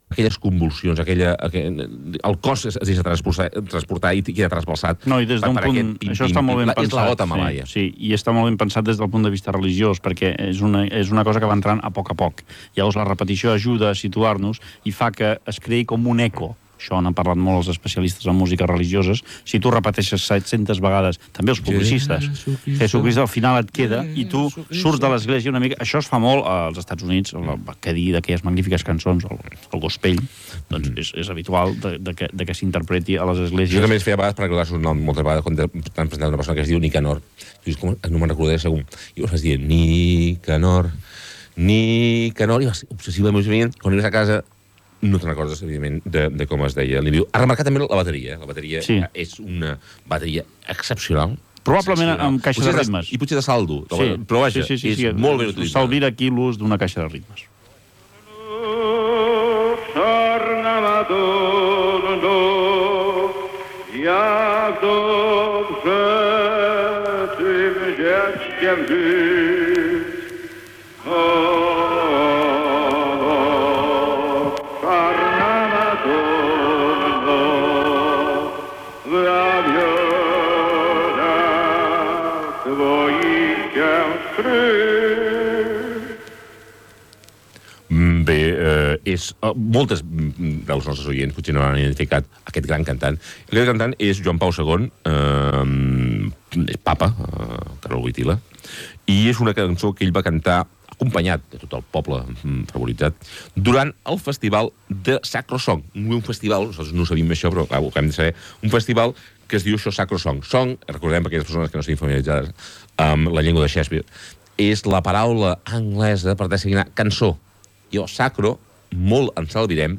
Comentaris sobre temes musicals, indicatiu, tema musical, comentari d'una versió de la nadala "El Fum, fum, fum" i comiat
Entreteniment